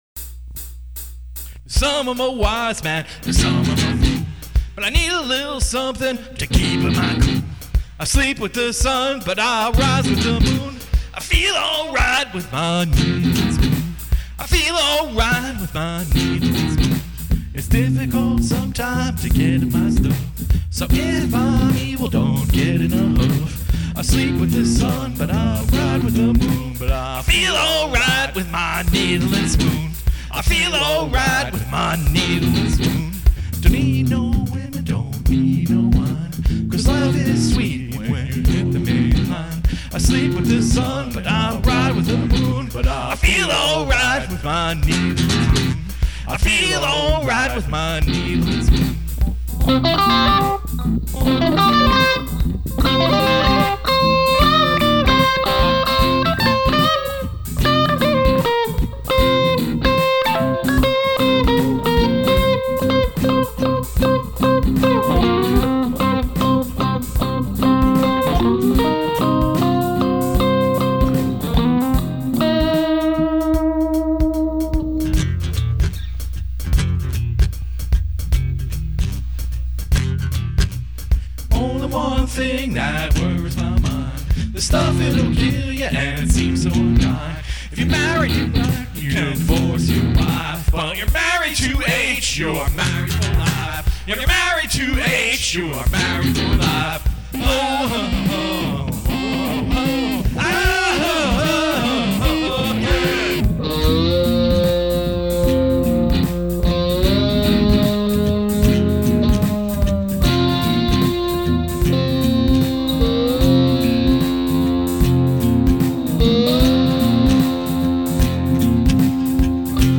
FUNKY ROCK N ROLL
it has always been one of my favorite blues rock tunes.